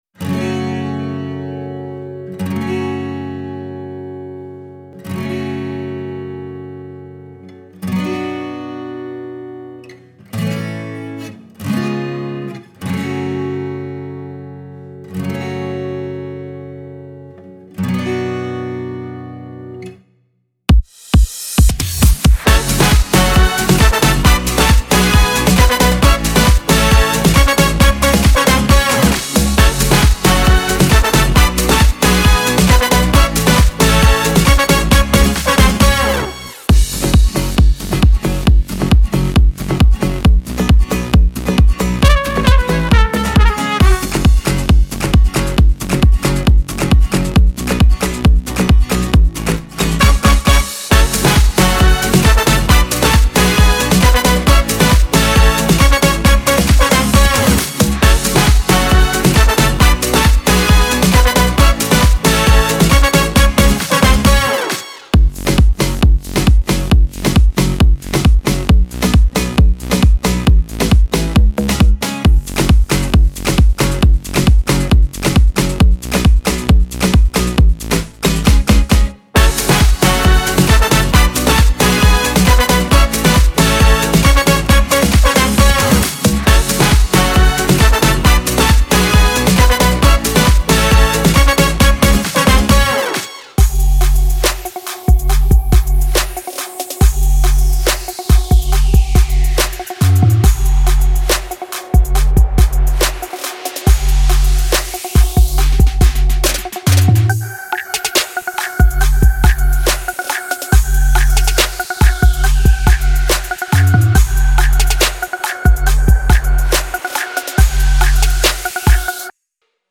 Свадебные